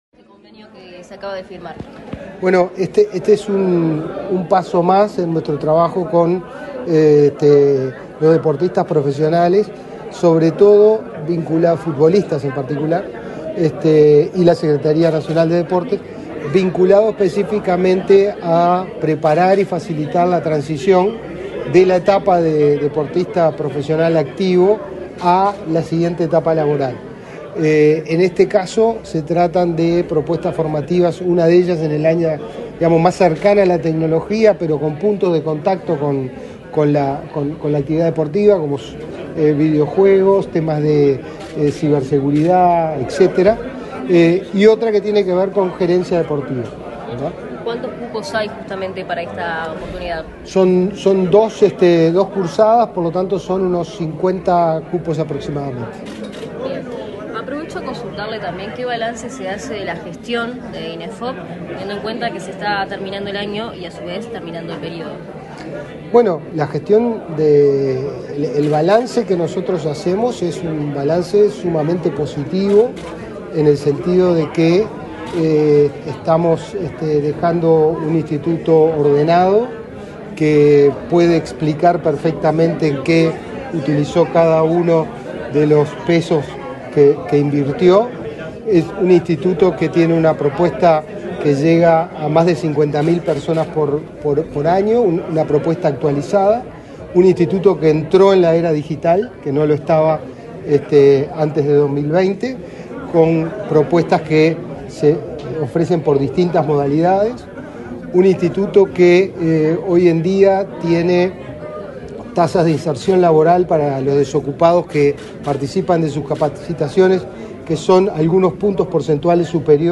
Declaraciones a la prensa del director del Inefop, Pablo Darscht
El Instituto Nacional de Empleo y Formación Profesional (Inefop), firmó, este 7 de noviembre, un convenio con la Secretaría Nacional del Deporte y la Mutual Uruguaya de Futbolistas Profesionales, para capacitar a deportistas en su inserción laboral posterior a las carreras. Tras el evento, el director del Inefop, Pablo Darscht, realizó declaraciones.